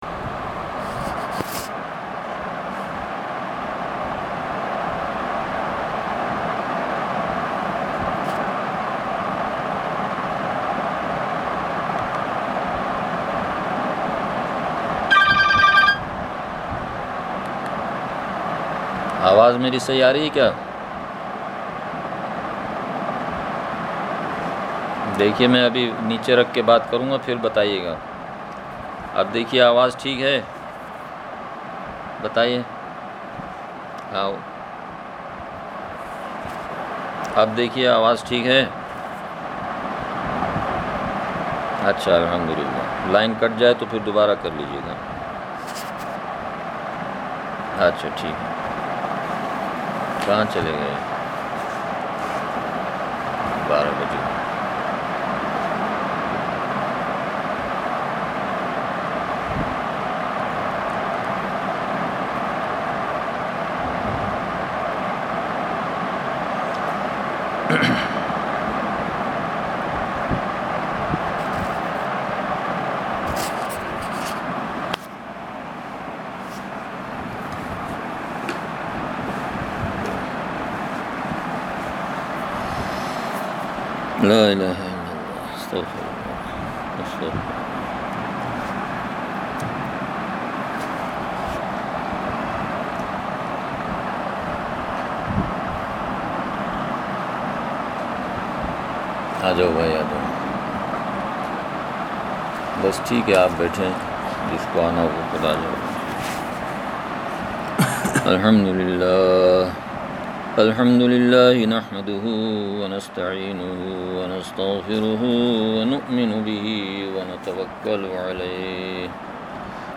بیان – سوات